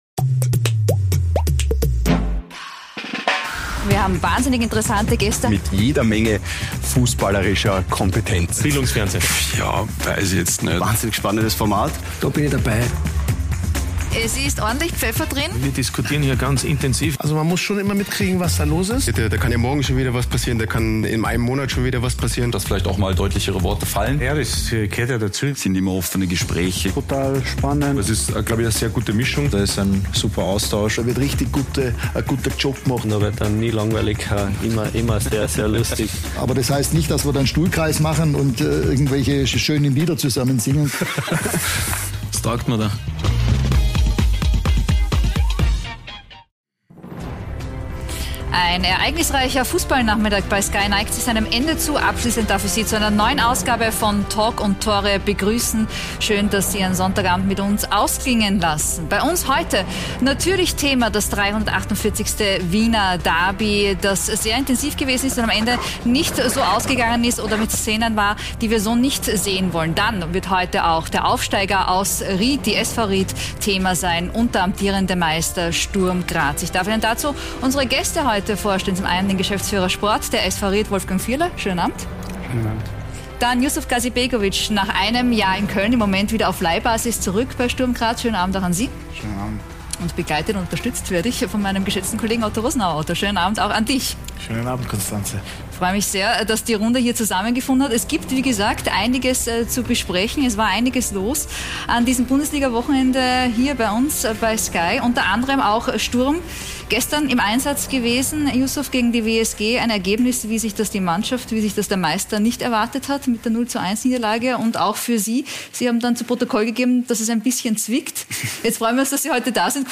Alexander Zickler und Leopold Querfeld nehmen im Studio Platz und sprechen über ihre Karrieren und Entwicklungen.